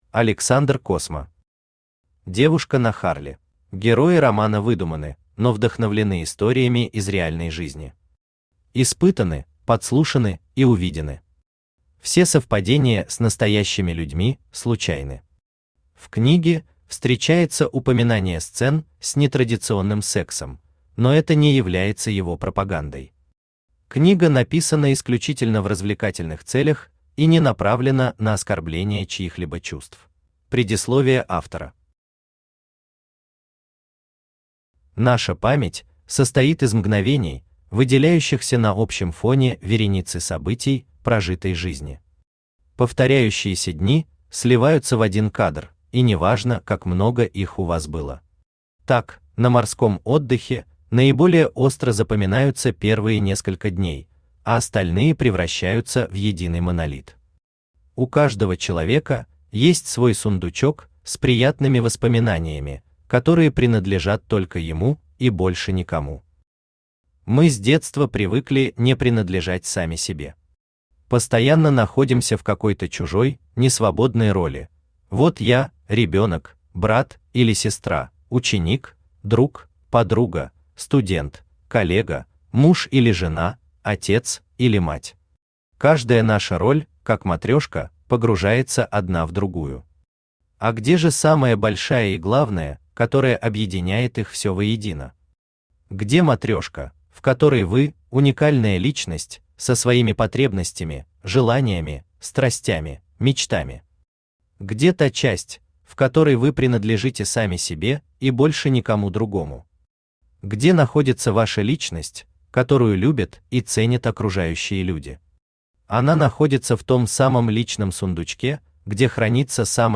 Аудиокнига Девушка на Harley | Библиотека аудиокниг
Aудиокнига Девушка на Harley Автор Александр Космо Читает аудиокнигу Авточтец ЛитРес.